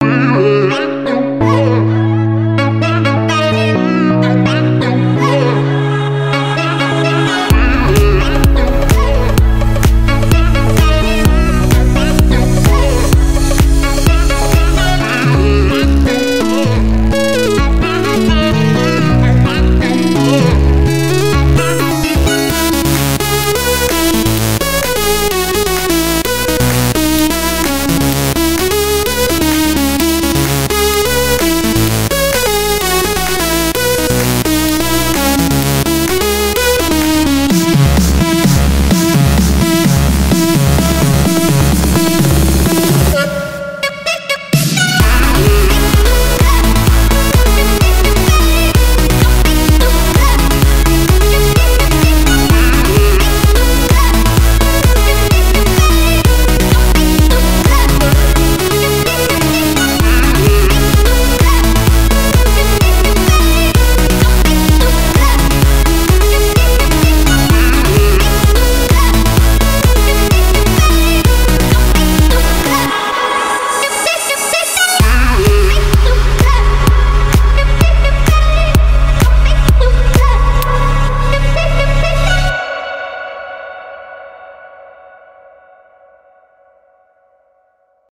BPM128
MP3 QualityMusic Cut